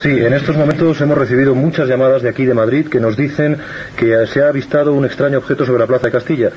Especial "Alerta OVNI". Trucades que avisen de l'avistament d'un OVNI a Madrid
Divulgació